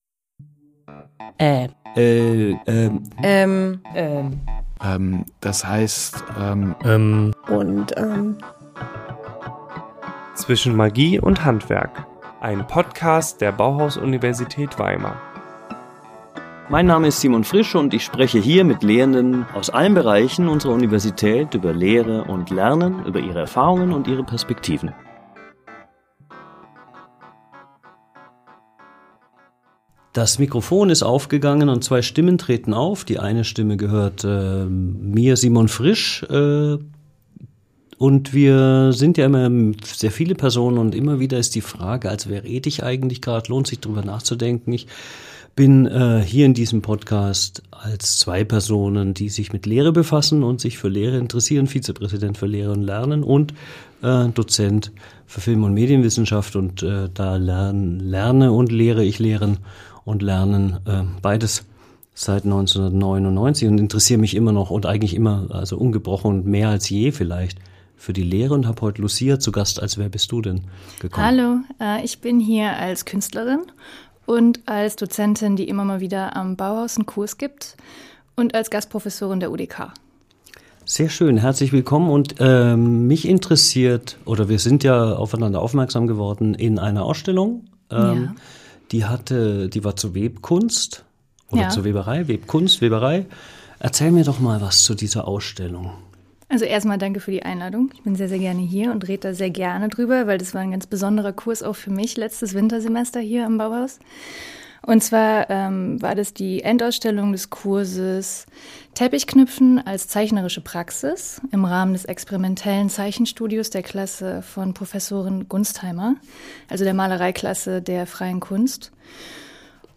Künstlerin und Gastprofessorin